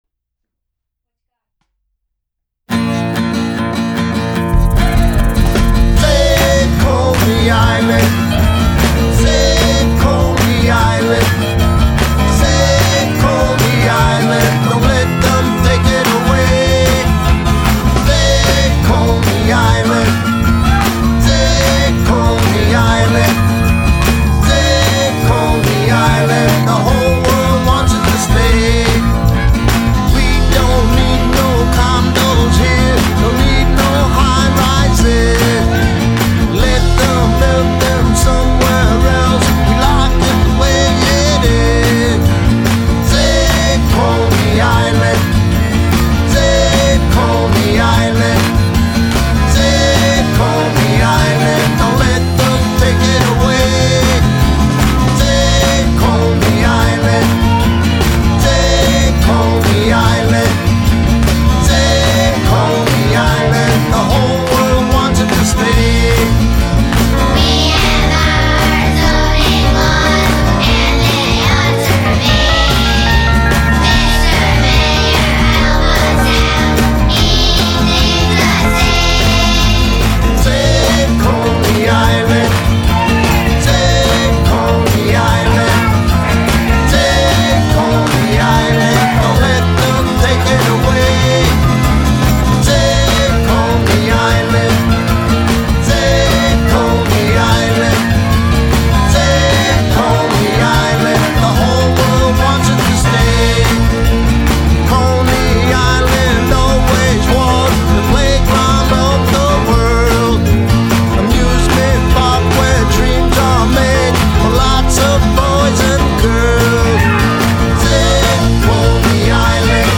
The principal opponent of the city’s plan to revitalize Coney Island was booed down at a public meeting on Monday night, prompting city officials to cut off the blustery politician mid-speech.
Carl Kruger (D–Brighton Beach) was the first in a parade of Brooklynites to come to the mic at a boisterous “public information session” at Lincoln HS, but he was the only one shouted down by the crowd.